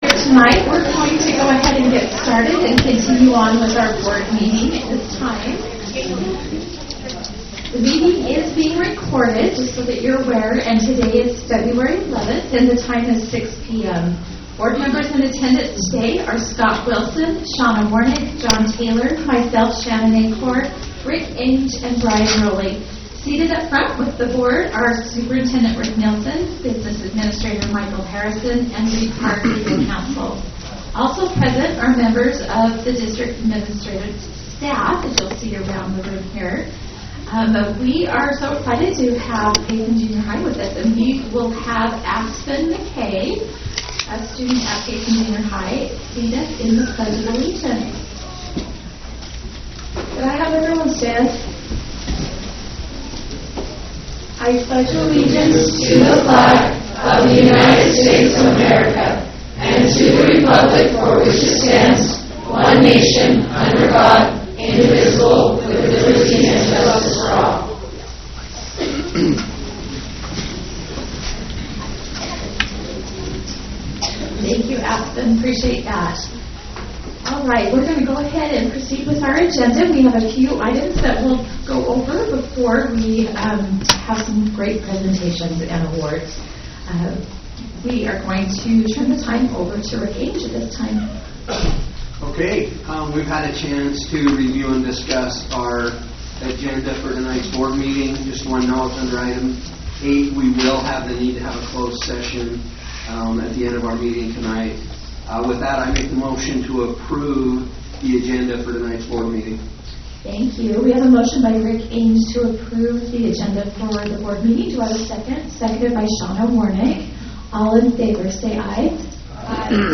School Board Meeting (General Session)